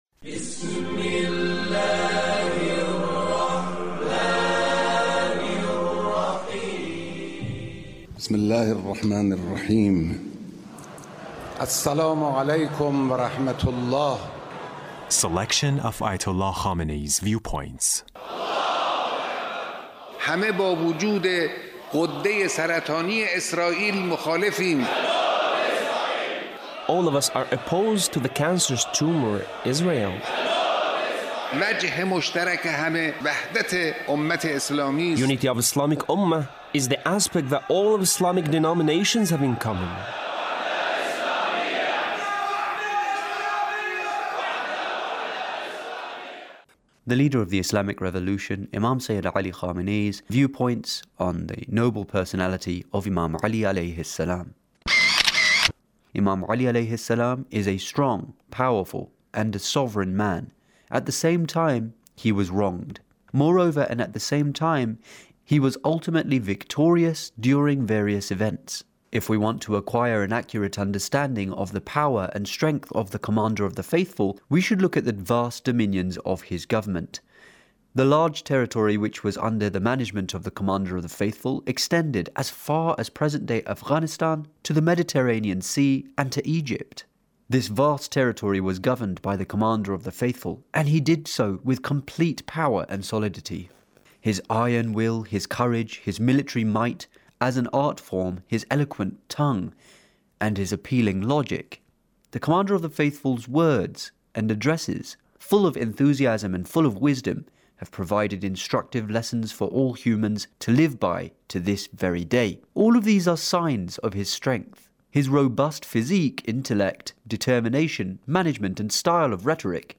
Leader's Speech (1673)